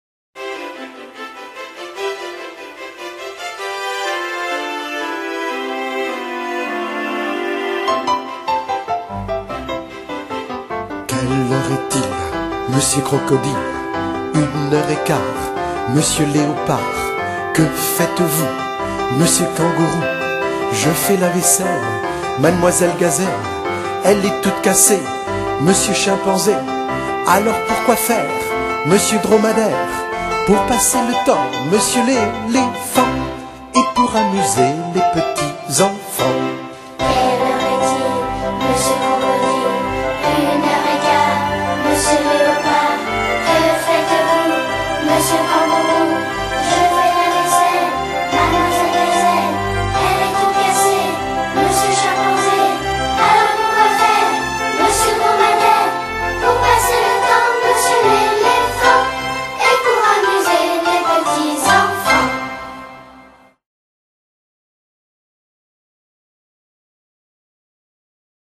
Chant à 1 voix
Version chantée :